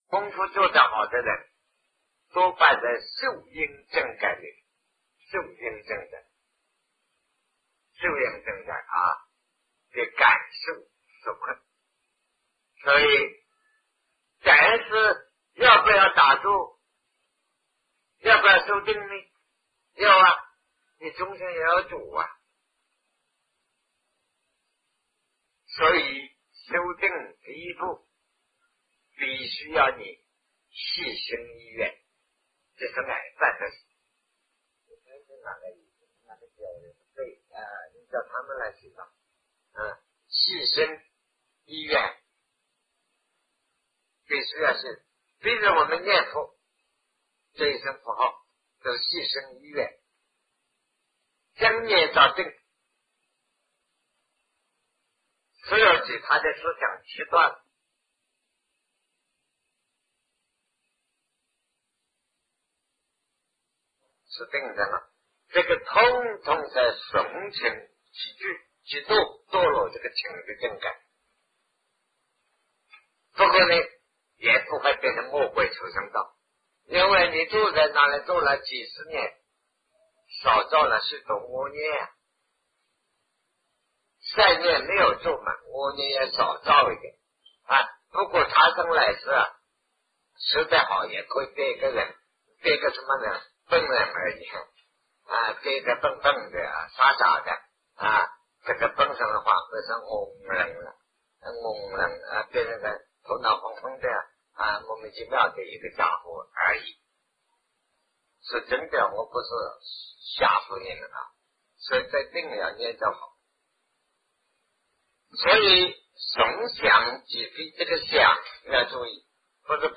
人投生的情形 南师讲唯识与中观（1980代初于台湾013(下)